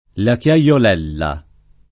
vai all'elenco alfabetico delle voci ingrandisci il carattere 100% rimpicciolisci il carattere stampa invia tramite posta elettronica codividi su Facebook Chiaiolella , la [ la k L a L ol % lla o la k L a L ol $ lla ] top.